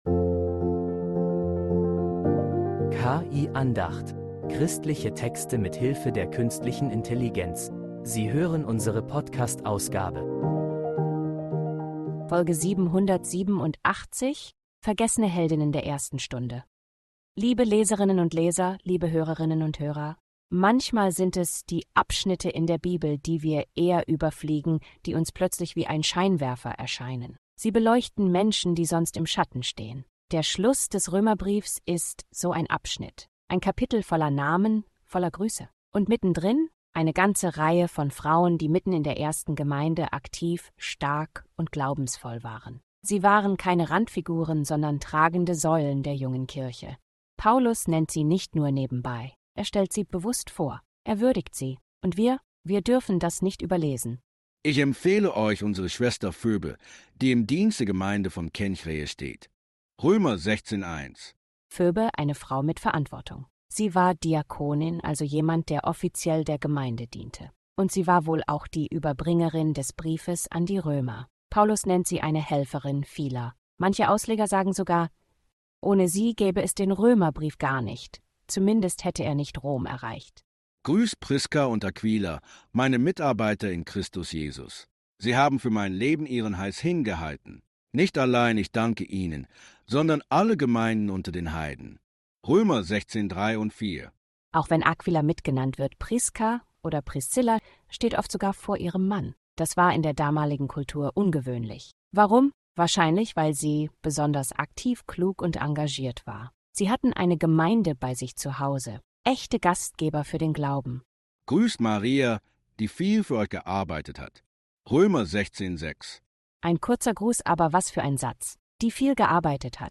KI-Andacht